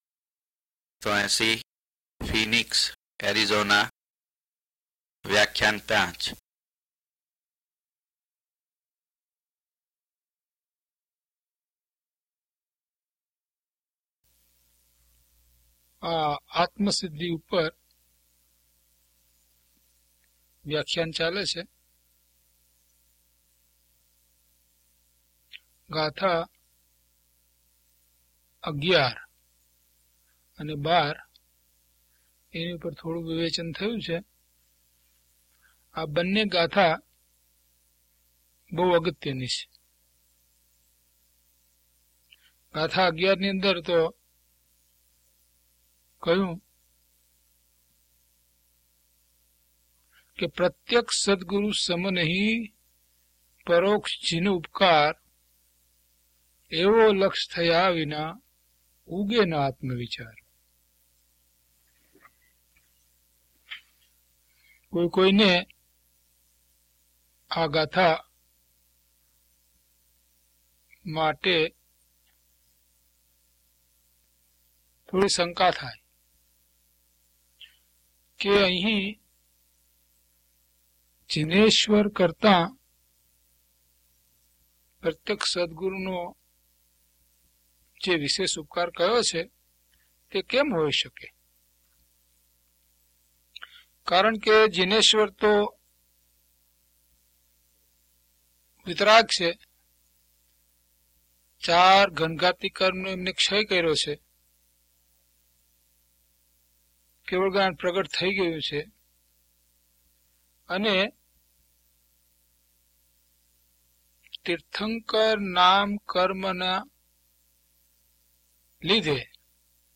DHP014 Atmasiddhi Vivechan 5 - Pravachan.mp3